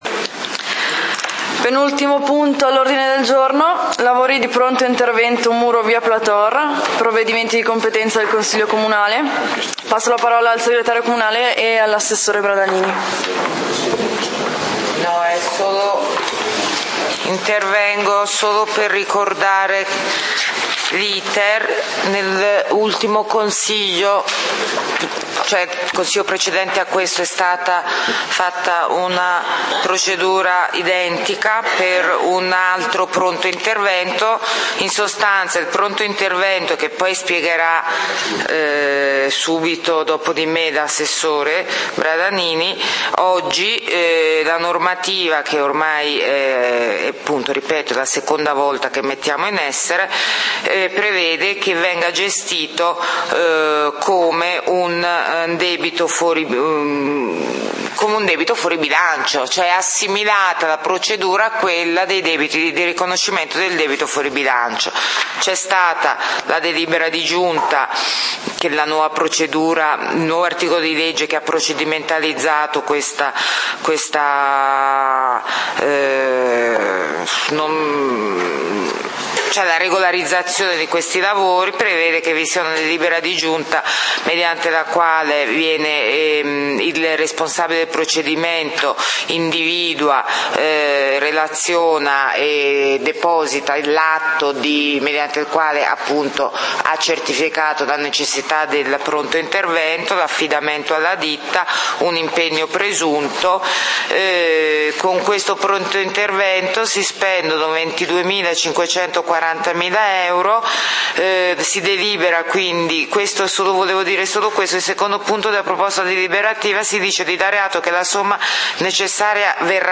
Punti del consiglio comunale di Valdidentro del 01 Agosto 2013